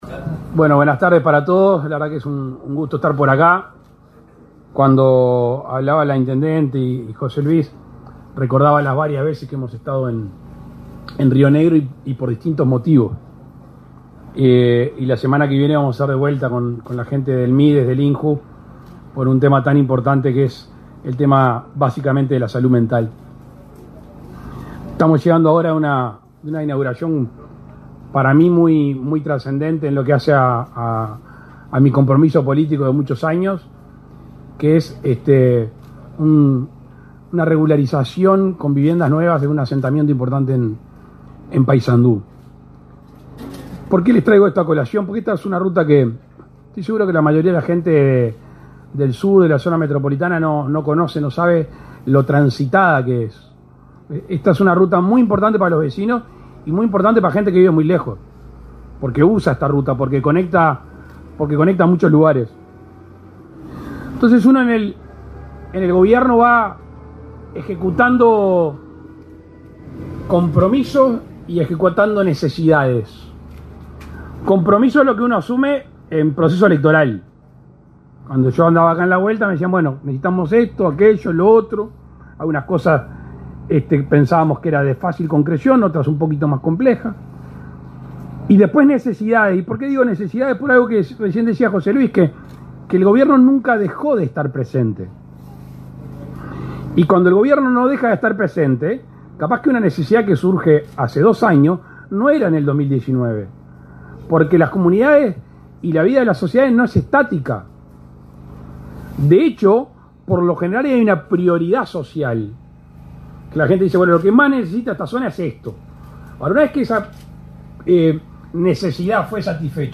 Palabras del presidene de la República, Luis Lacalle Pou 17/10/2024 Compartir Facebook X Copiar enlace WhatsApp LinkedIn El presidente de la República, Luis Lacalle Pou, participó, este 17 de octubre, en la reinauguración de la ruta n.° 25, en el tramo entre Tres Bocas y Young, en el departamento de Río Negro.